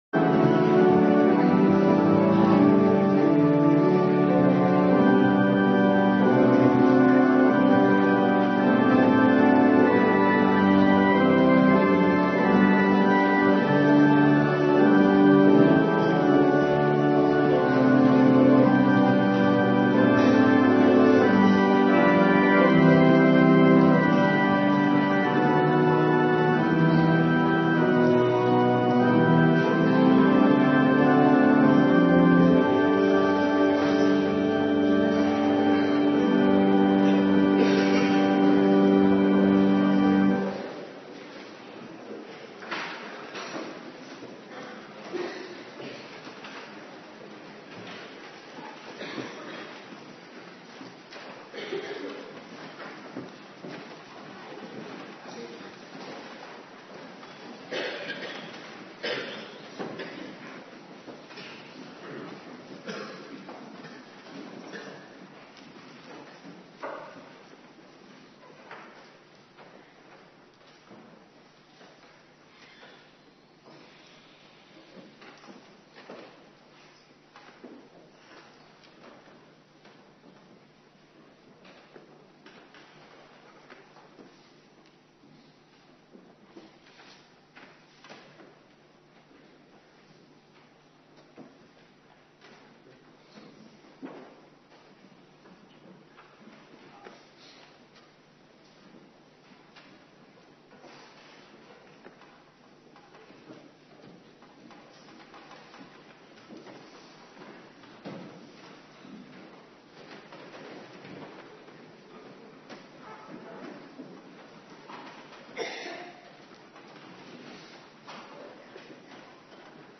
Morgendienst
09:30 t/m 11:00 Locatie: Hervormde Gemeente Waarder Agenda: Abonneren op deze agenda Kerkdiensten Terugluisteren Johannes 14:7-21